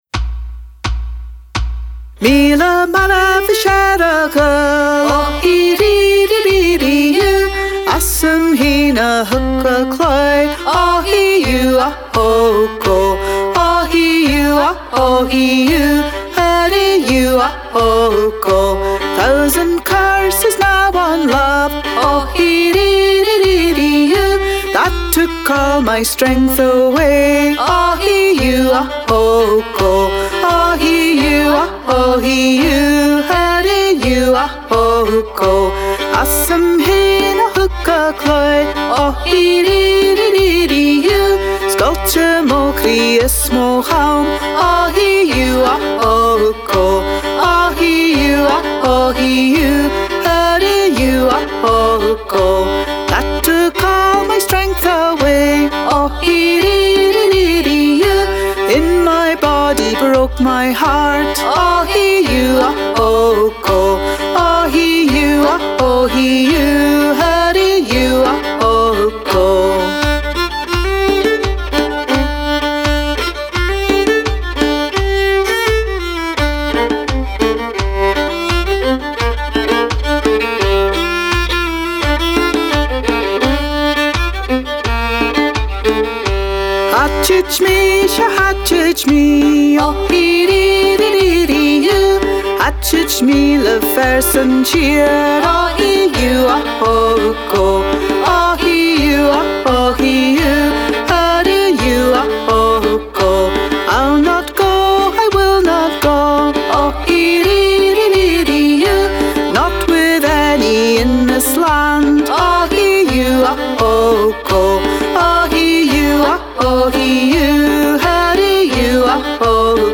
Mile Marbhaisg air a' Ghaol (A Thousand Curses on Love) - another waulking song!
Also mp3 of fiddle & singing.
the mp3 above is my favorite so far.  also really like the 35 degrees south above.  to me these are sounding more appalachian-ish.   idk.. hear the similarities more than irish stuff.   making me want to listen to some more like this.